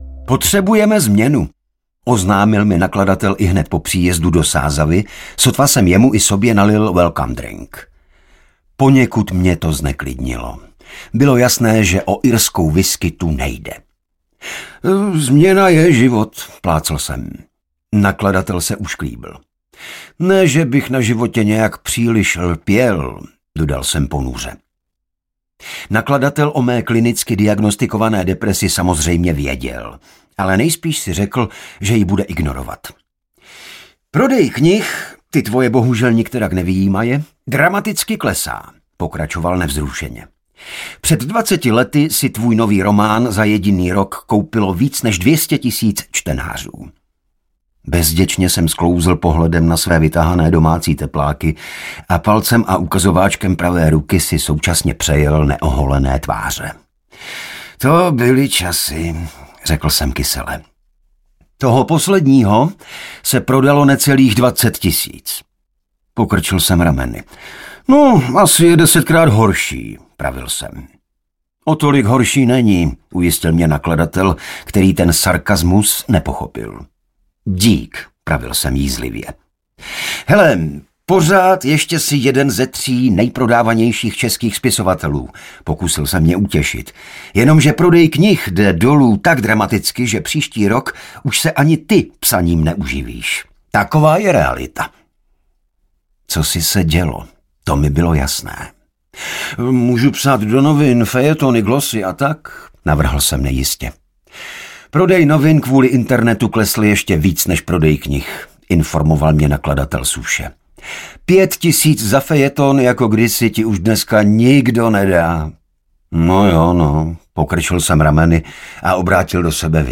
Muž a žena audiokniha